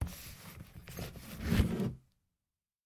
На этой странице собраны звуки библиотеки: тихий шелест страниц, шаги между стеллажами, отдаленные голоса читателей.
Шум библиотеки с читателями